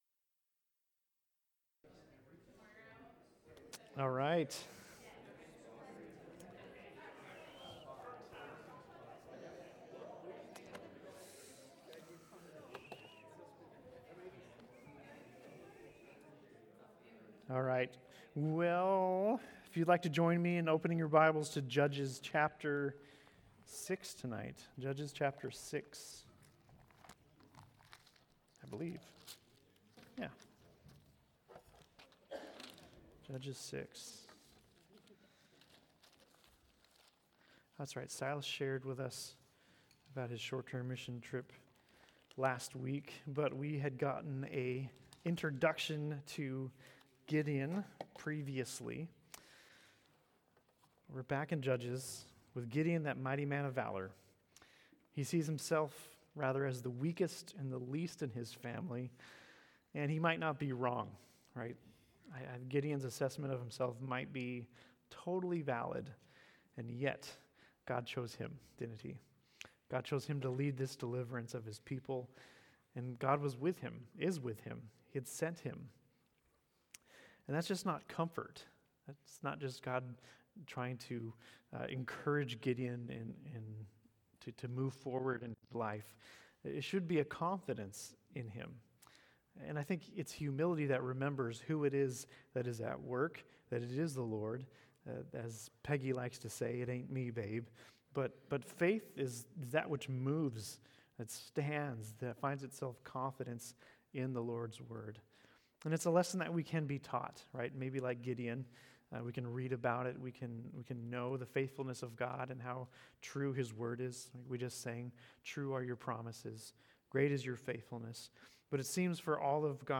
Sermons - Calvary Chapel Eureka